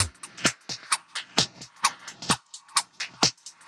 Index of /musicradar/uk-garage-samples/130bpm Lines n Loops/Beats
GA_BeatErevrev130-05.wav